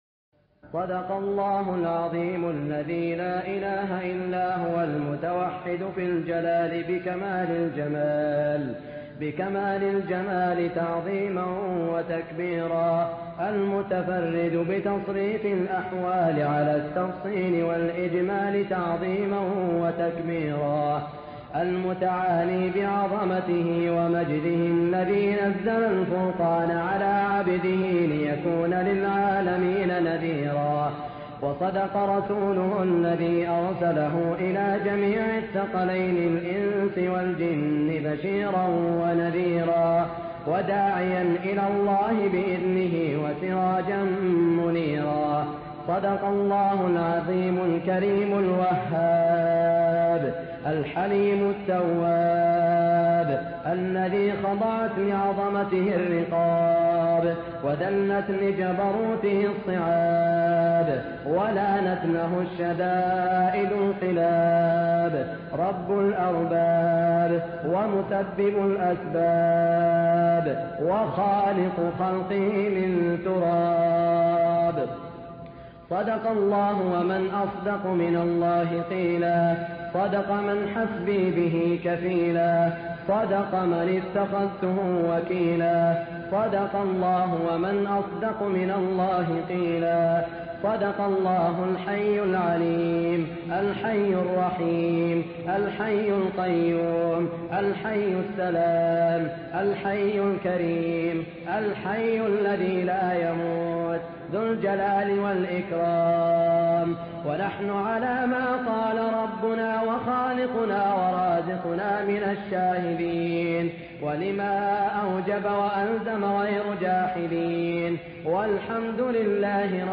دعاء ختم القران في الرياض > تلاوات الشيخ سعود الشريم خارج الحرم > تلاوات و جهود الشيخ سعود الشريم > المزيد - تلاوات الحرمين